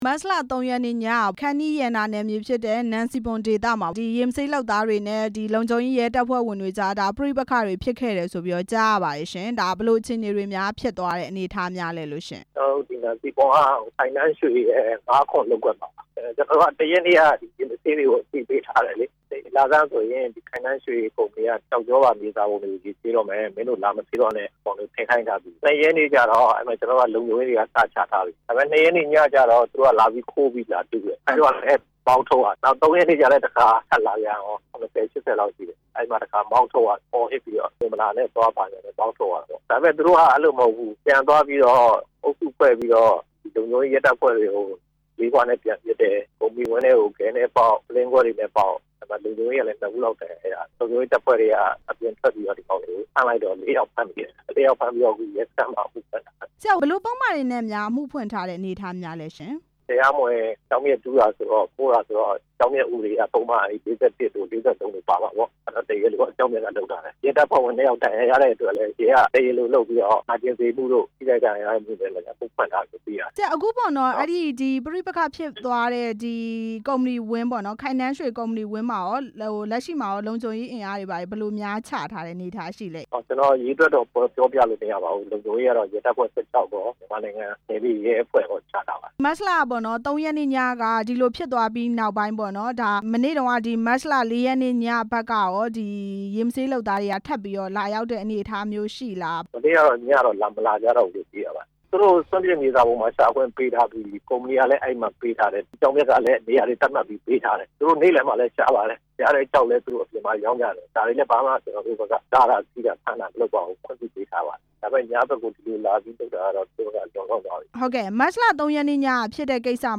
ရေမဆေးသမားတွေနဲ့ ရဲတပ်ဖွဲ့ဝင်တွေ ပြဿနာဖြစ်ပွားတဲ့အကြောင်း မေးမြန်းချက်